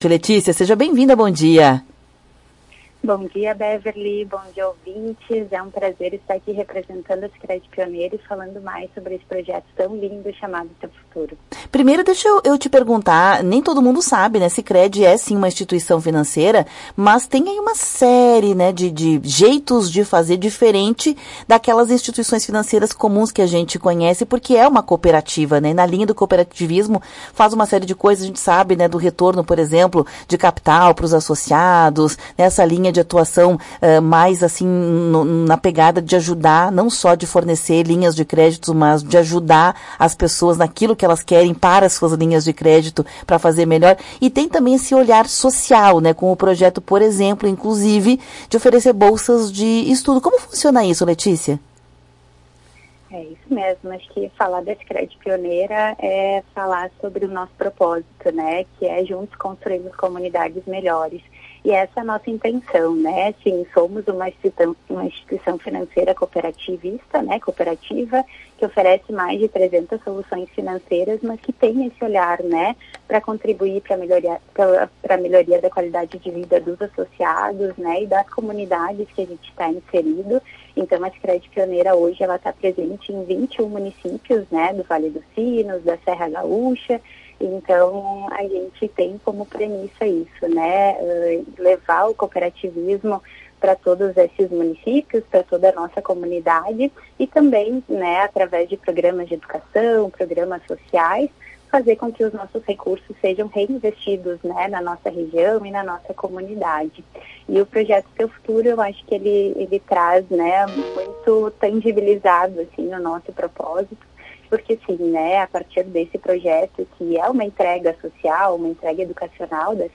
falou sobre o assunto em entrevista ao programa Temática.